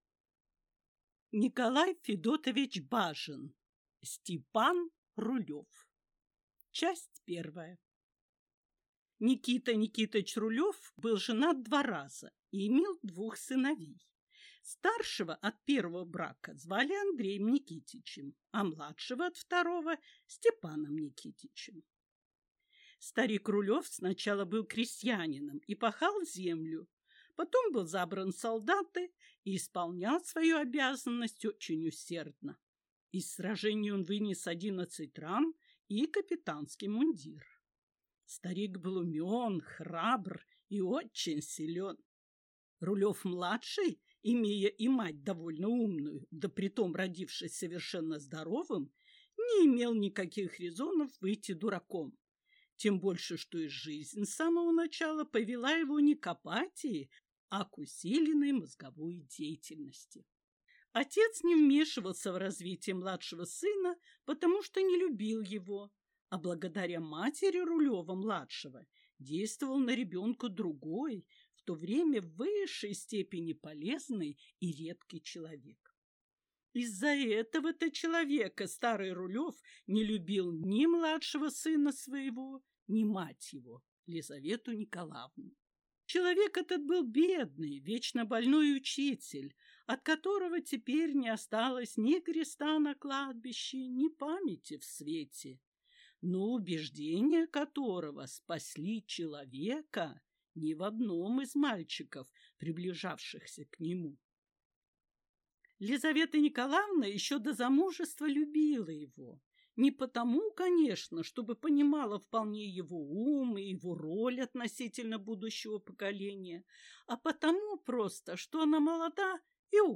Аудиокнига Степан Рулев | Библиотека аудиокниг